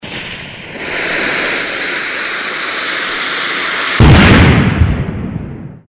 Поджог фитиля динамита и громкий бах